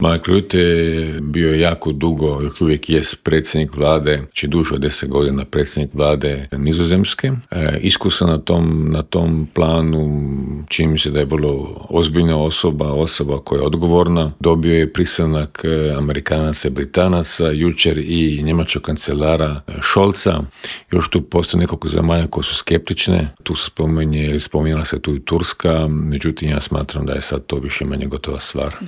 ZAGREB - Uoči druge godišnjice početka ruske agresije na Ukrajinu, u Intervjuu Media servisa razgovarali smo s bivšim ministrom vanjskih poslova Mirom Kovačem, koji nam je kratko proanalizirao trenutno stanje u Ukrajini, odgovorio na pitanje nazire li se kraj ratu, a osvrnuo se i na izbor novog glavnog tajnika NATO saveza i na nadolazeći sastanak Europskog vijeća.